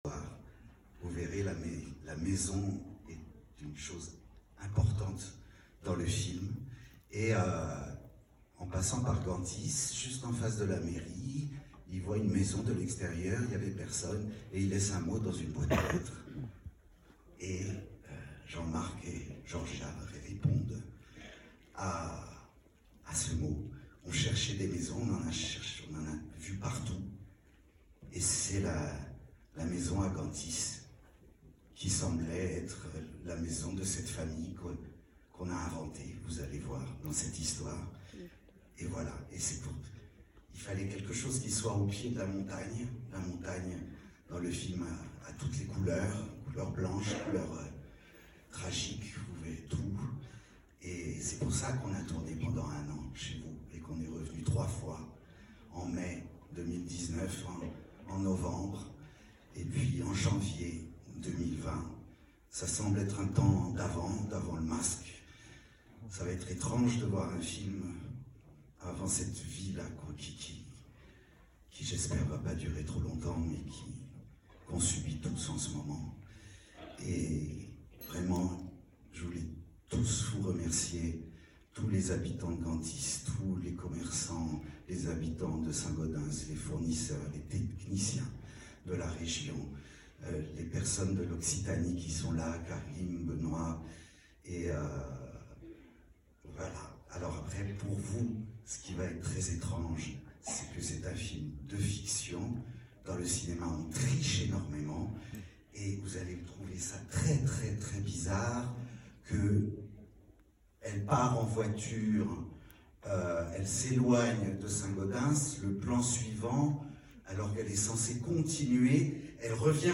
Mathieu Amalric présente son film aux habitants de Saint-Gaudens.